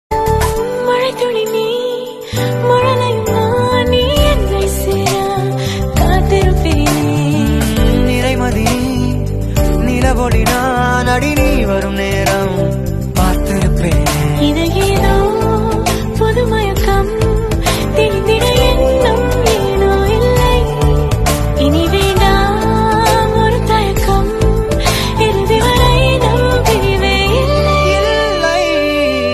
melodious Tamil tune
romantic ringtones
a soft and emotional vibe